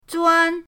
zuan1.mp3